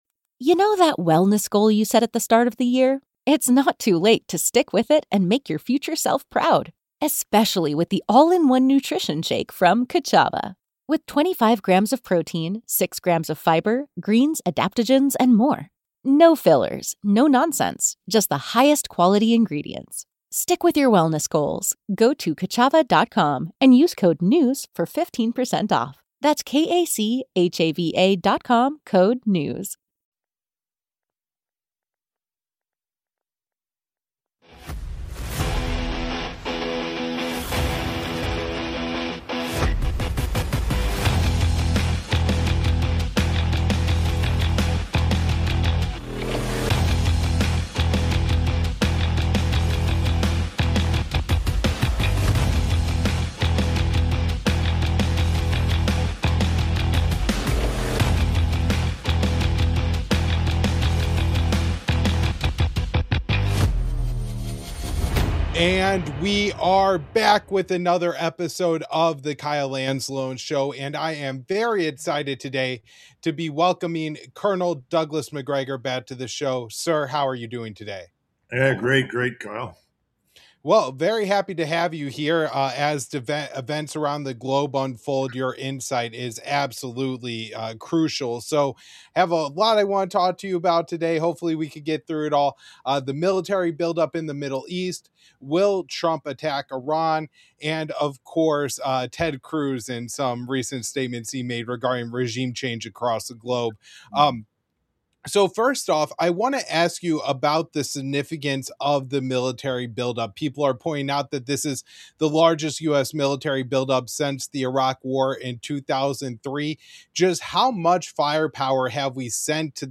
A wall of U.S. air and naval power now sits within reach of Iran, but does massed hardware equal a winning strategy? We sit down with Colonel Douglas Macgregor to map the real shape of a campaign: suppressing integrated air defenses, cracking command-and-control, and hunting Iran’s theater ballistic missiles before they launch.